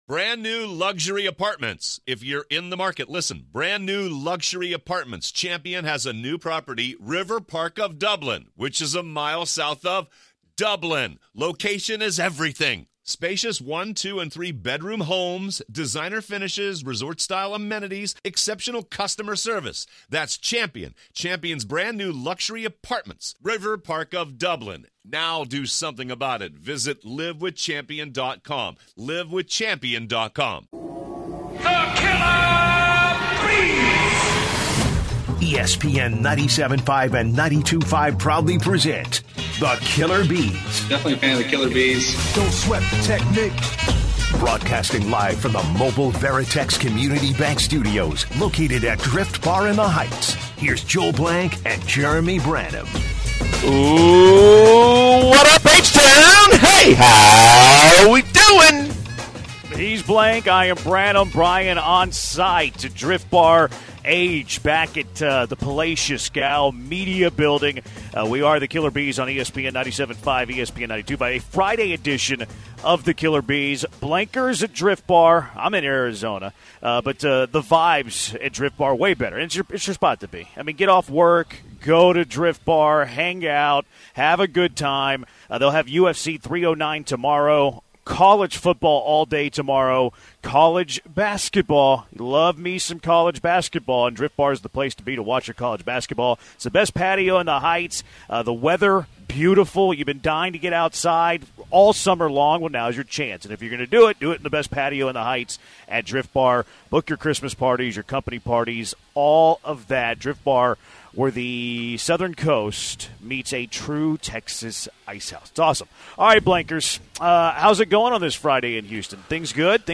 11/15 Hour 1- Soquon Barkley Going to Get Joe Schoen and Brian Daboll Fired? - Live from Drift Bar in the Heights